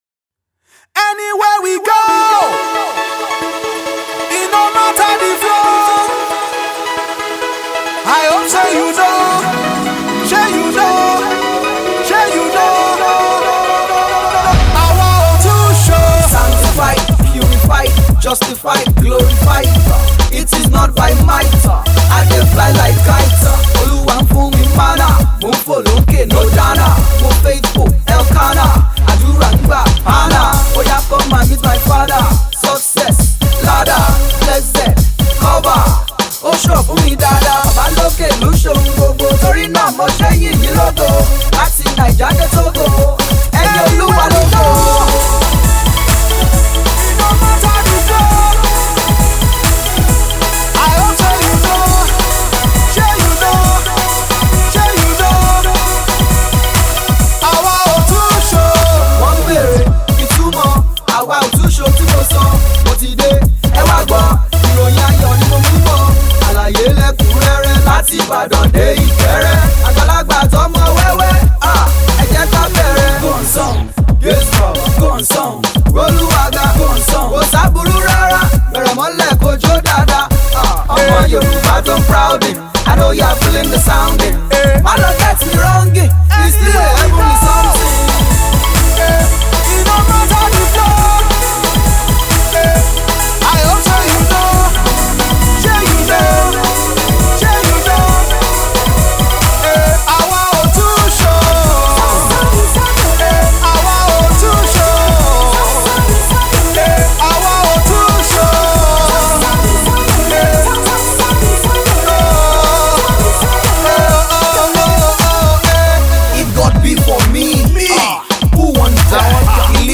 March 31, 2025 Publisher 01 Gospel 0